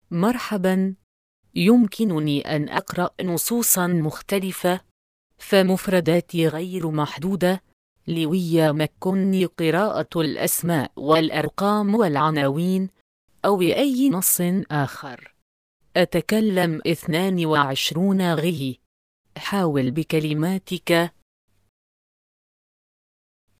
Texte de d�monstration lu par Salma (Acapela High Quality Text To Speech Voices; distribu� sur le site de Nextup Technology; femme; arabe